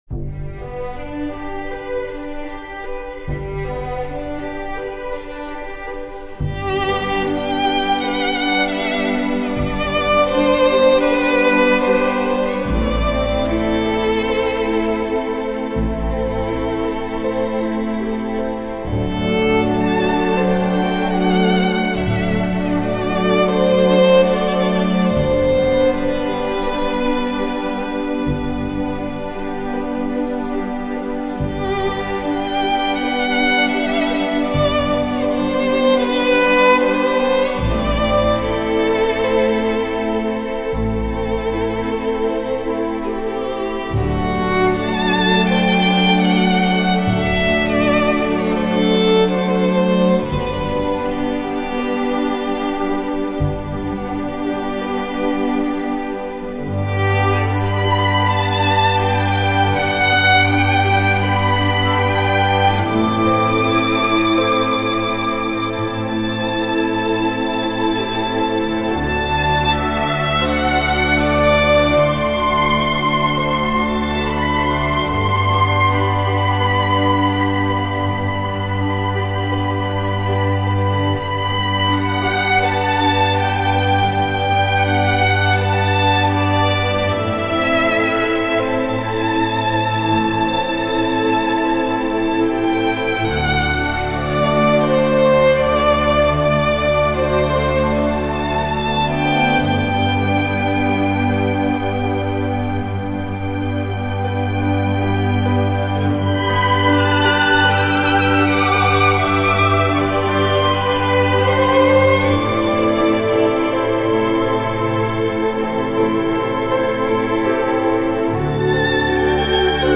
O R C H E S T R A L   &   S Y M P H O N I C
Recorded at the Jack Singer Concert Hall, Calgary.